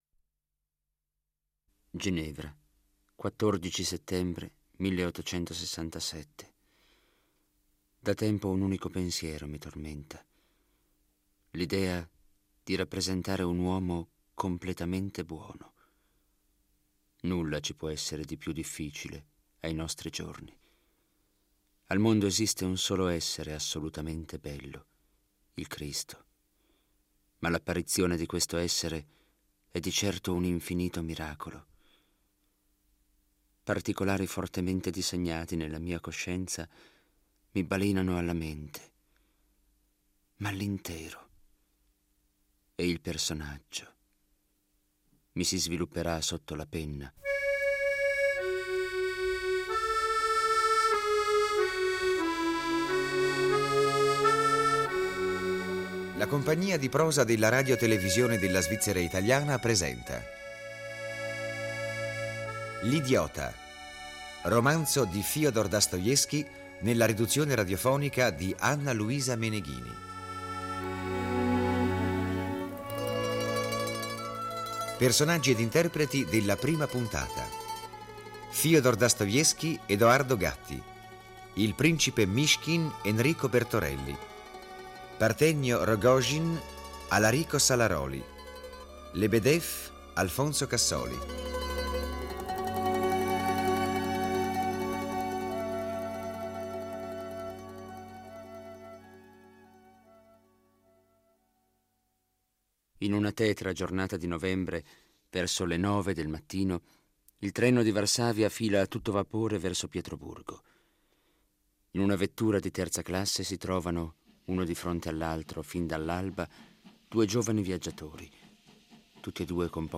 di Fëdor Michajlovic Dostoevskij - Adattamento radiofonico